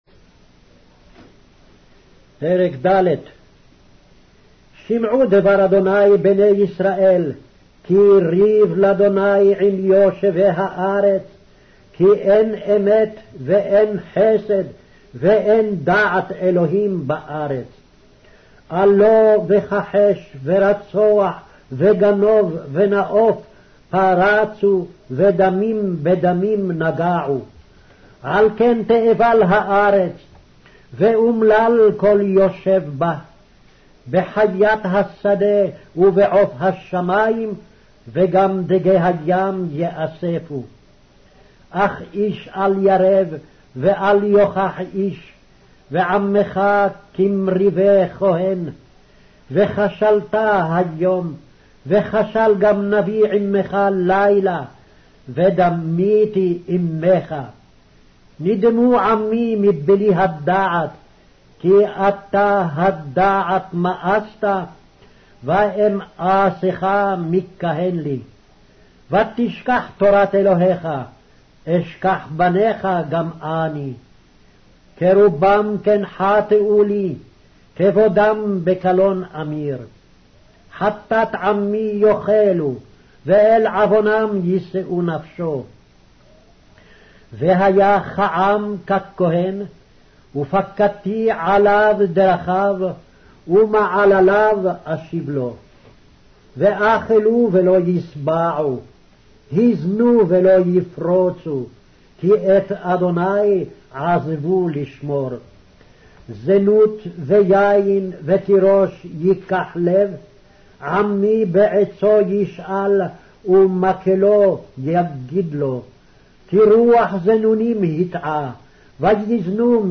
Hebrew Audio Bible - Hosea 4 in Irvas bible version